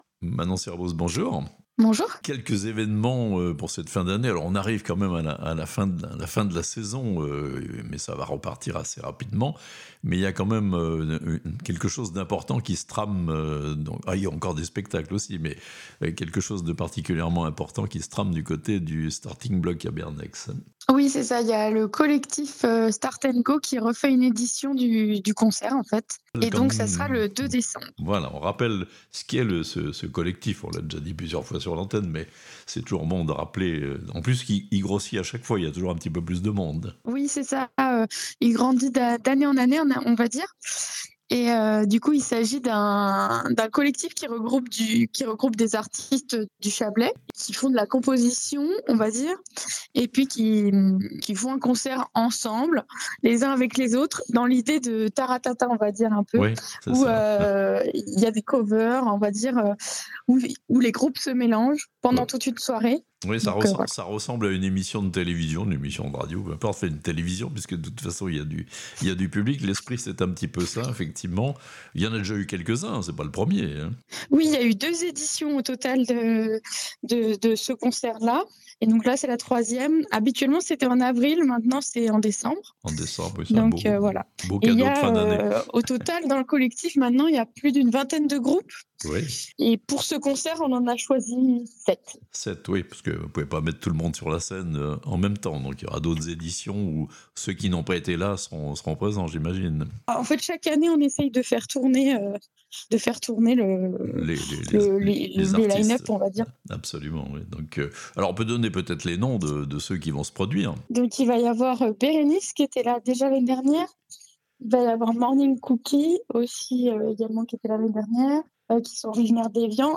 Un concert du collectif des artistes chablaisiens prochainement au Starting-Block à Bernex (interview)